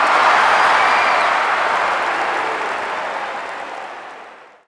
Goal.wav